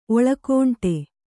♪ oḷakōṇṭe